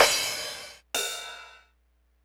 cymbal.wav